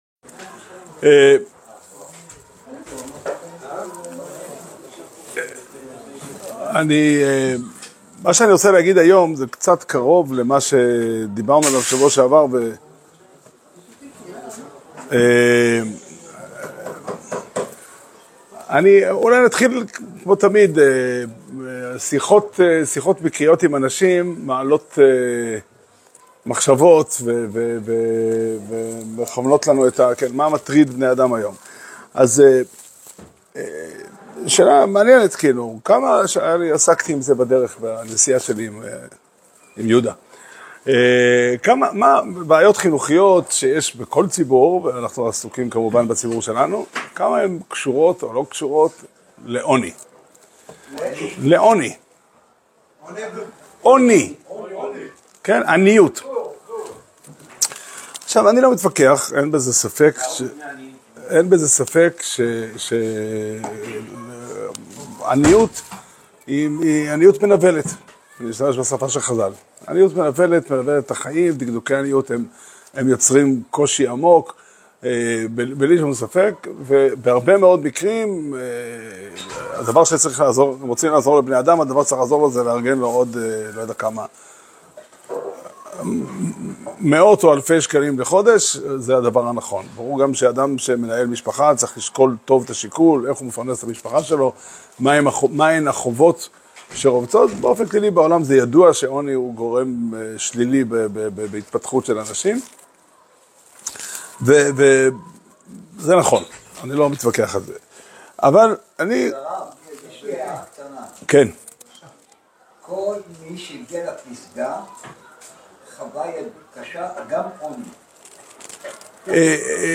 שיעור שנמסר בבית המדרש פתחי עולם בתאריך י"א כסלו תשפ"ה